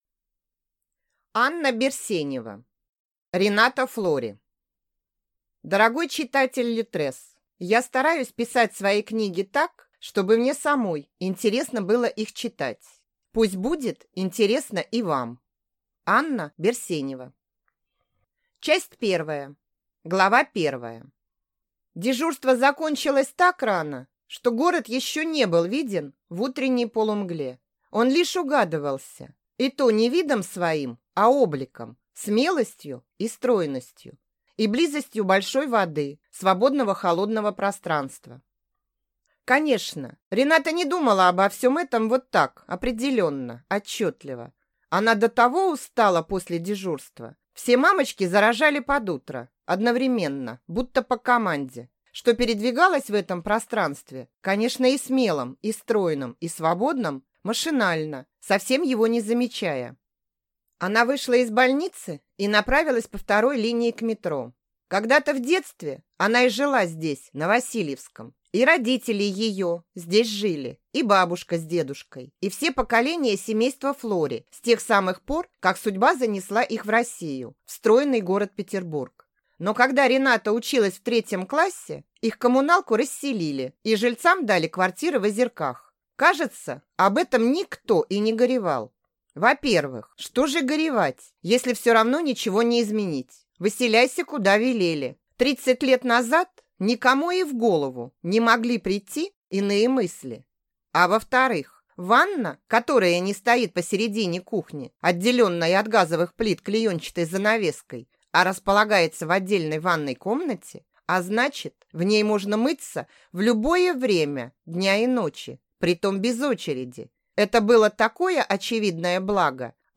Аудиокнига Рената Флори